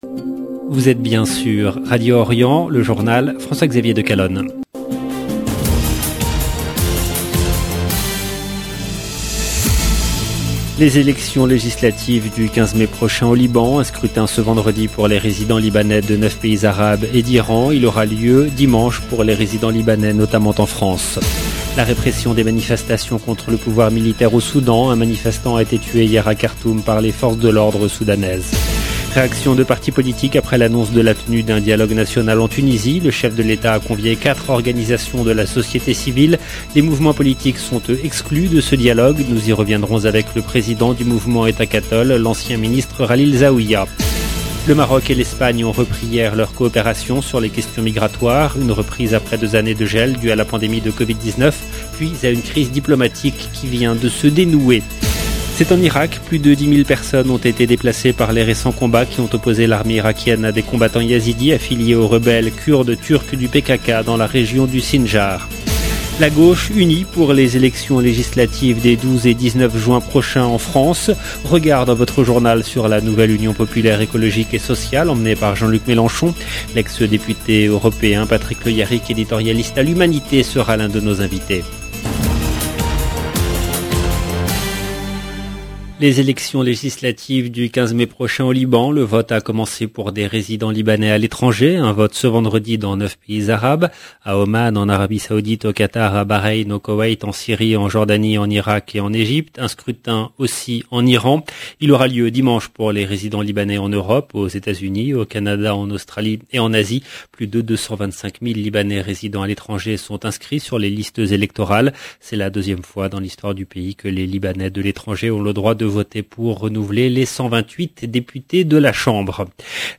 EDITION DU JOURNAL DU SOIR EN LANGUE FRANCAISE DU 6/5/2022